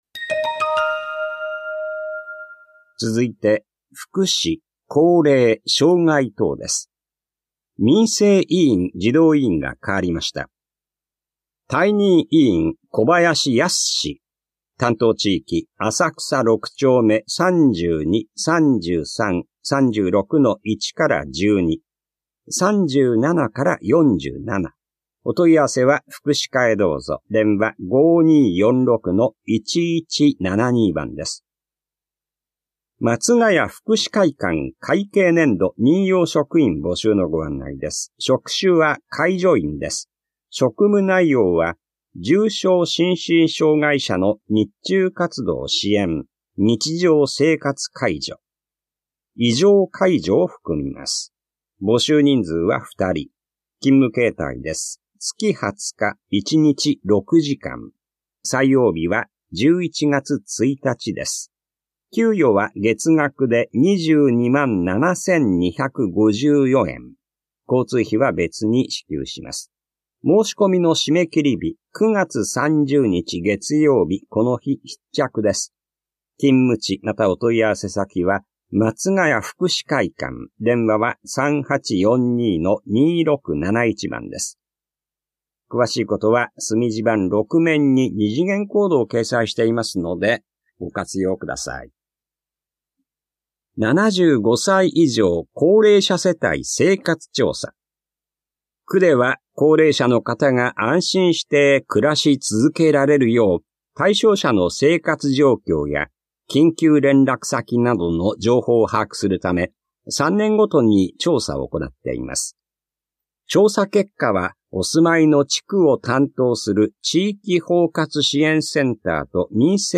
広報「たいとう」令和6年9月5日号の音声読み上げデータです。